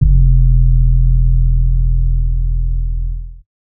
TS 808_7.wav